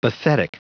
Prononciation du mot bathetic en anglais (fichier audio)